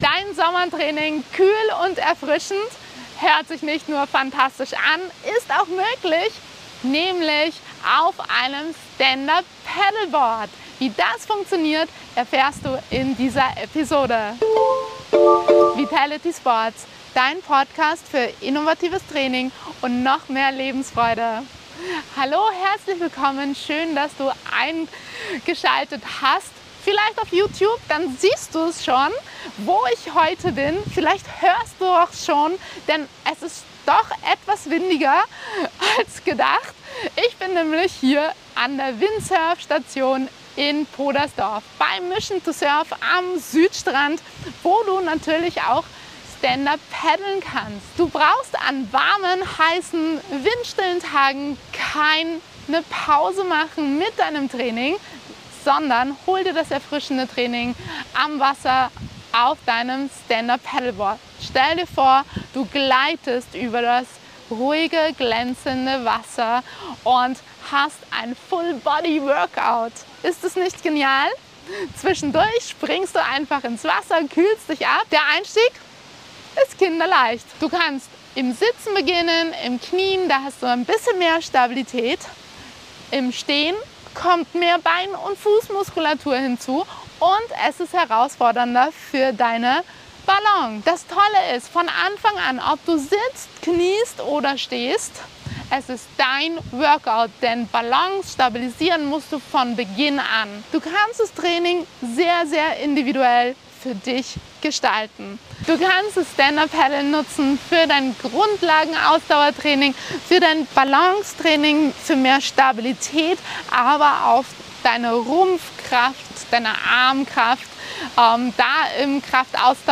Mission To Surf Station in Podersdorf.